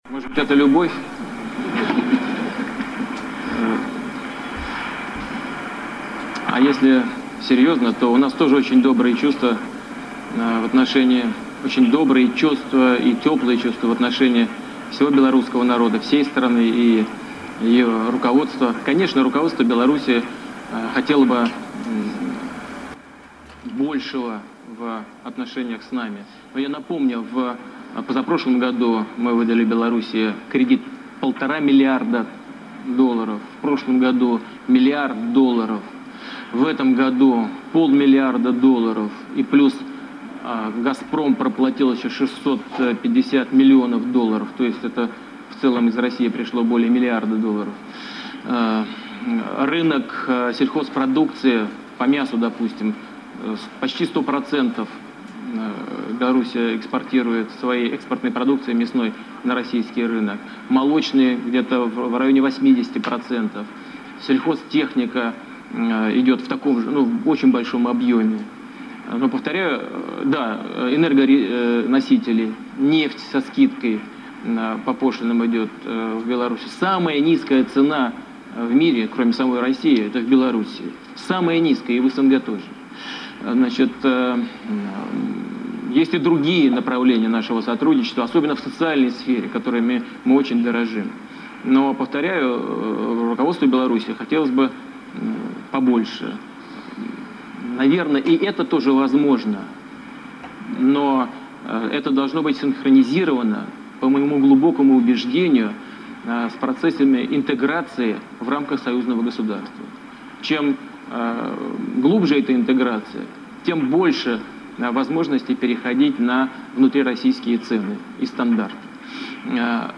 Уладзімер Пуцін падчас сваёй штогадовай тэлеразмовы з жыхарамі Расеі адказаў на пытаньне, чаму ён не рэагуе на пэрсанальную крытыку з боку А. Лукашэнкі на яго адрас, якая апошнім часам часта гучыць